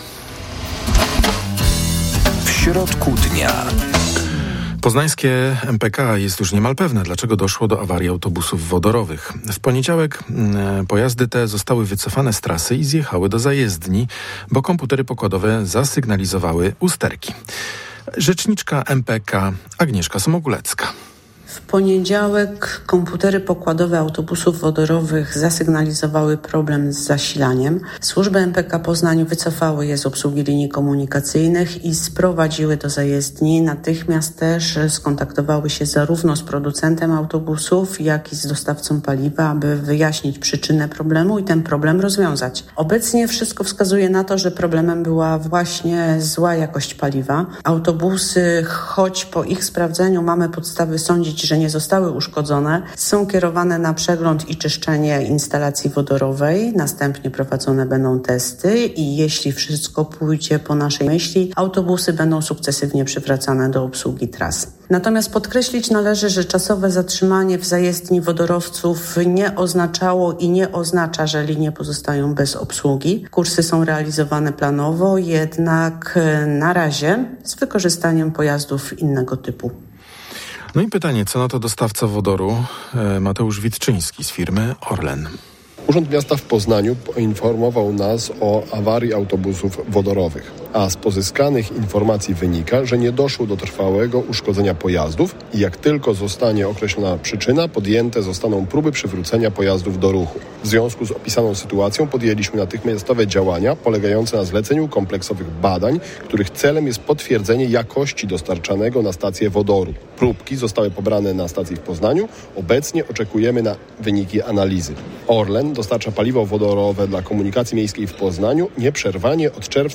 Dziś w audycji „W środku dnia” porozmawiamy o tym ze specjalistą z Politechniki Poznańskiej.